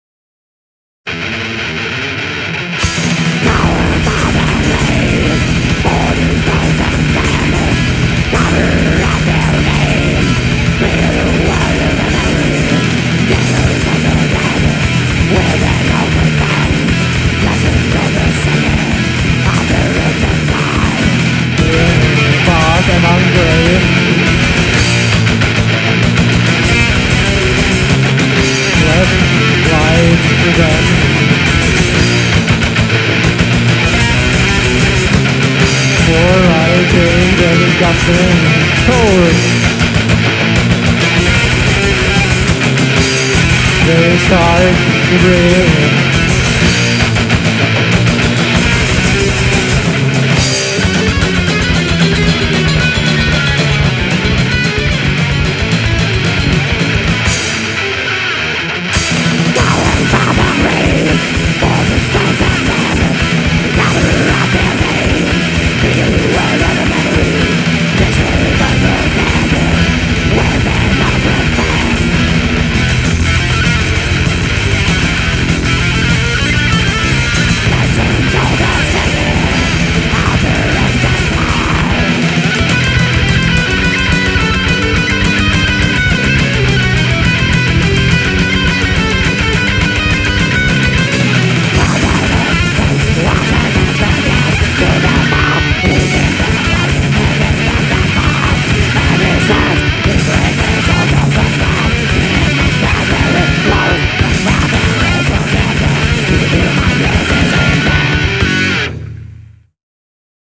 která hrála neskutečnej prasopal